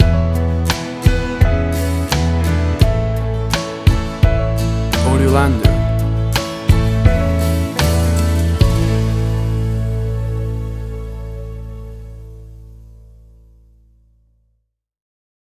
WAV Sample Rate: 16-Bit stereo, 44.1 kHz
Tempo (BPM): 86